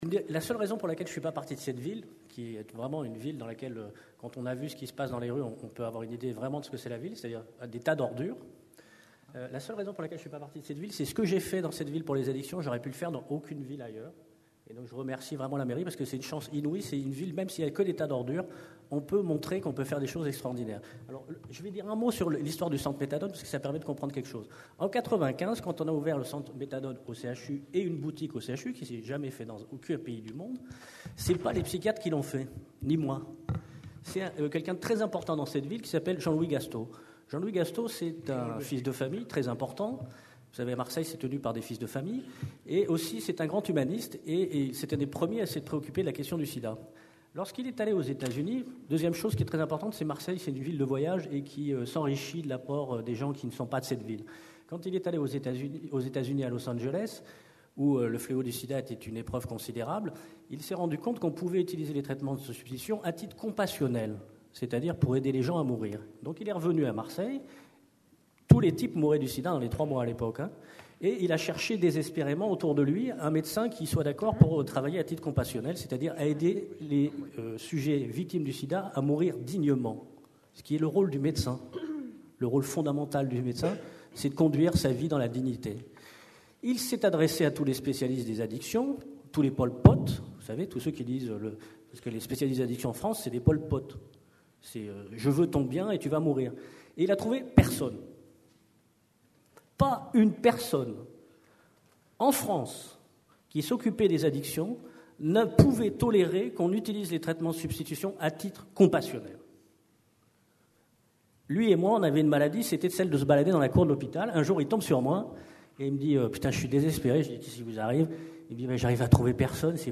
CNIPsy 2010 Marseille : 7ème Congrès National des Internes en Psychiatrie (CNIPsy).